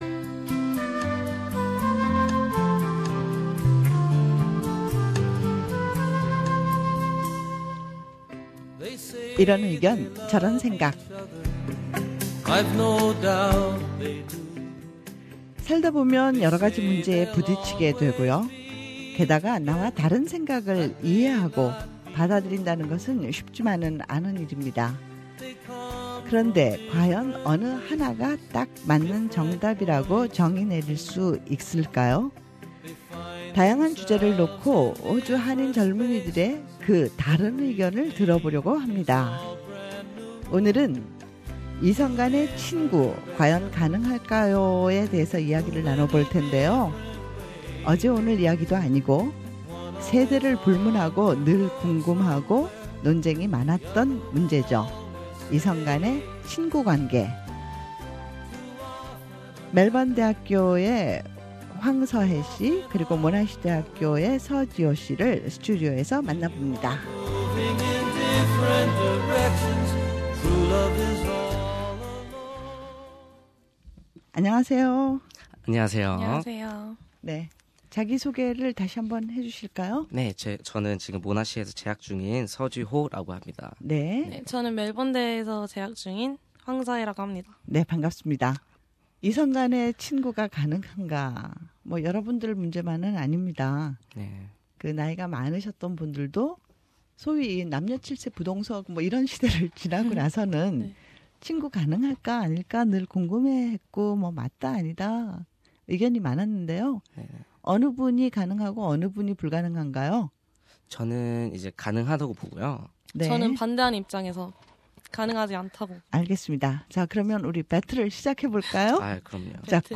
우리 주변의 다양한 이슈에 대해 호주에 사는 한인 청년들은 어떤 생각을 갖고 있을까요? '이런 생각, 저런 의견' 이번 주 토론 주제는 '이성 간의 친구, 가능한가?' 입니다.